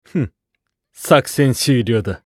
厨二病ボイス～戦闘ボイス～